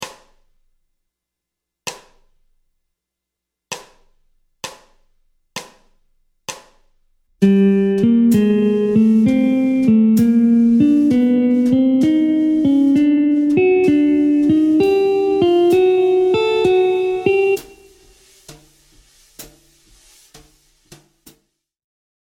Gamme mineure harmonique ( I – mode mineur harmonique)
Montée de gamme
Gamme-bop-asc-Pos-42-C-min-harm.mp3